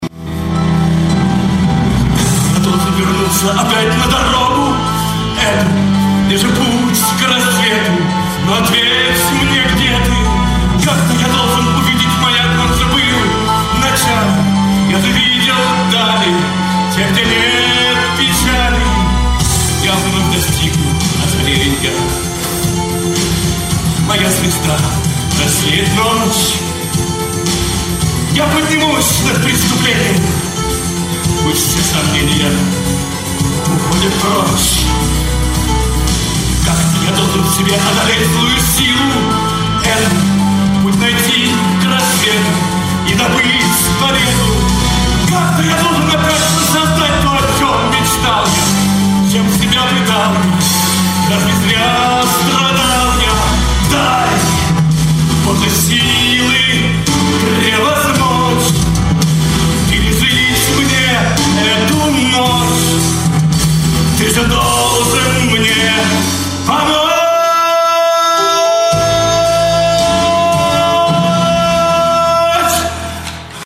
Музыкальная драма в 2-х частях
Аудио запись со спектакля от 26.05.2005 г*.